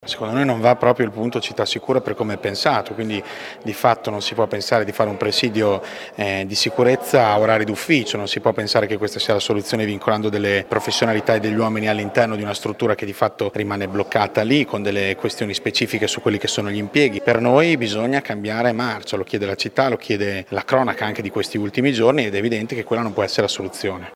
Dura l’opposizione che critica l’impiego di vigili nel presidio Modena Città sicura in Piazza Matteotti, giudicato inefficace. Sentiamo il capogruppo di Fratelli d’Italia Luca Negrini.